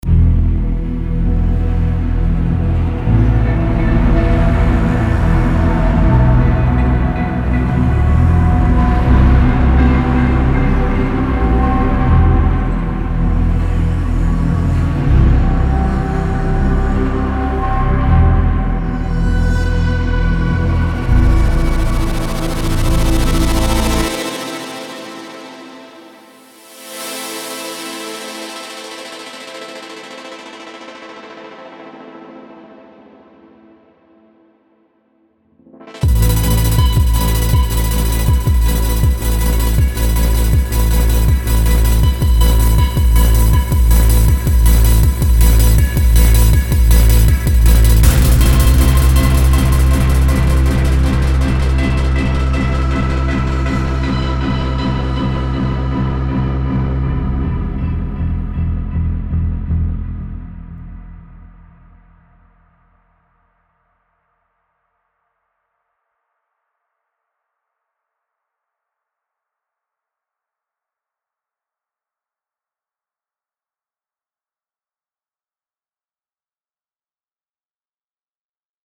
Working on a soundtrack, WIP track above